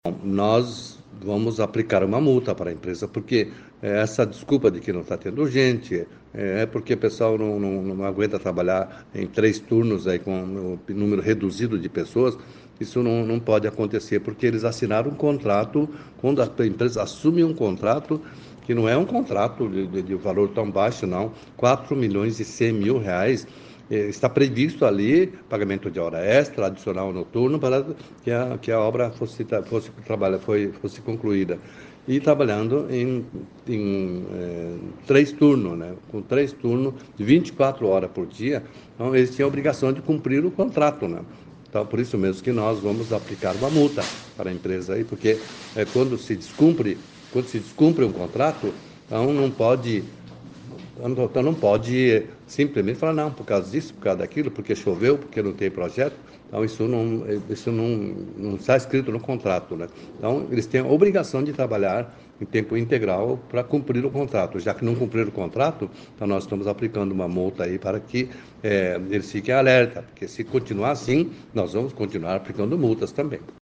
Ouça o que diz o presidente da Câmara, Mário Hossokawa: